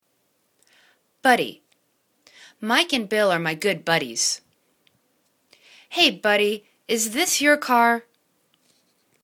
bud.dy     /'bidi/    n